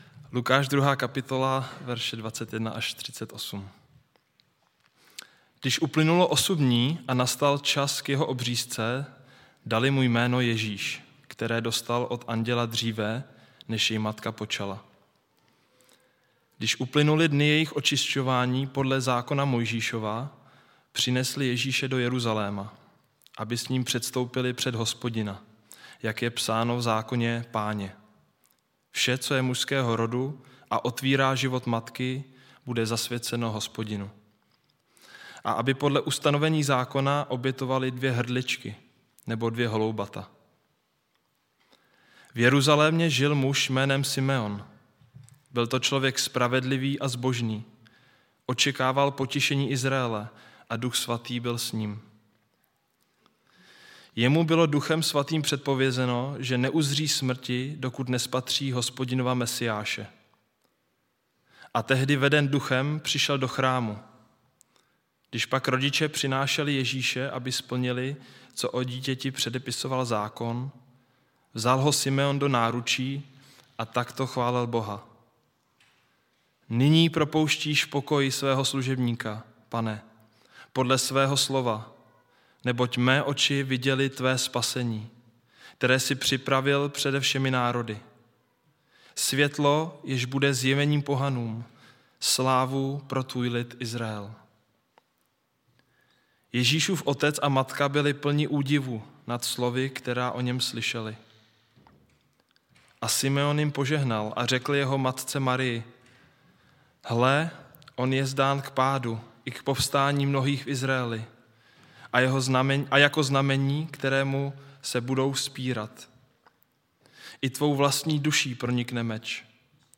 Nedělní bohoslužba na Boží hod Vánoční